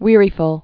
(wîrē-fəl)